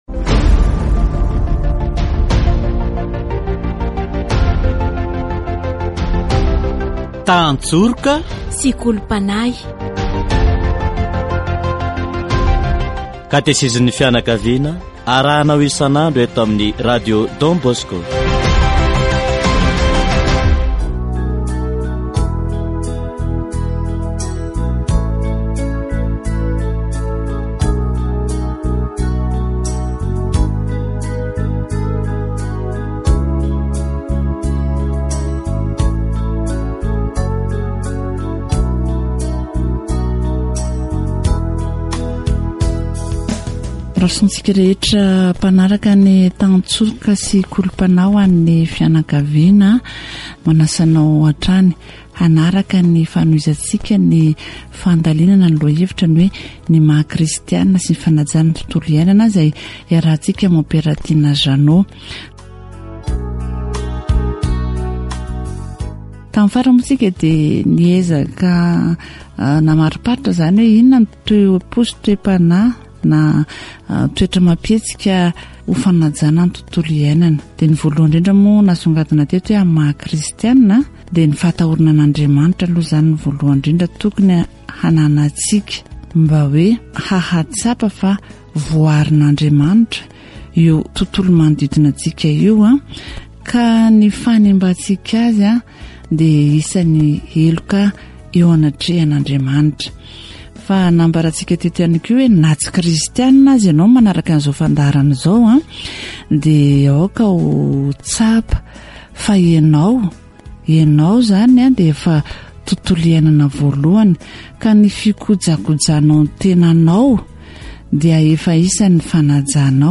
Katesizy momba ny tontolo iainana